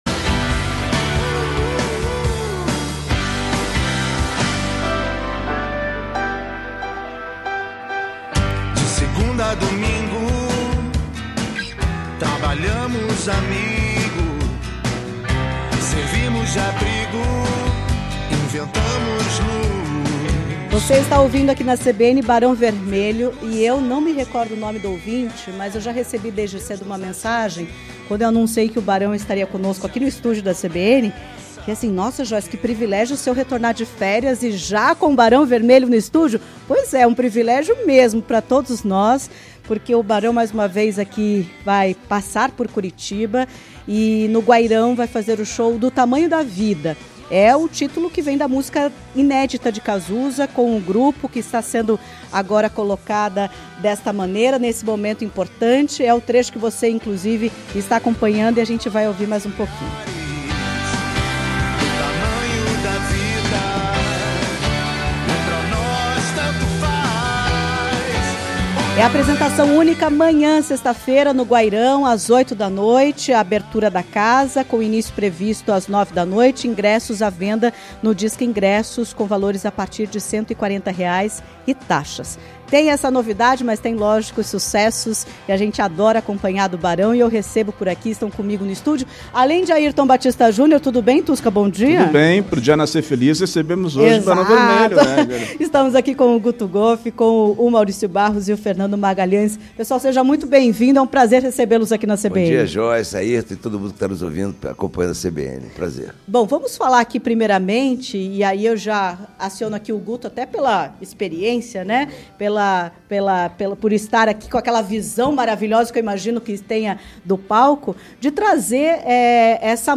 A banda Barão Vermelho esteve no estúdio da Rádio CBN Curitiba.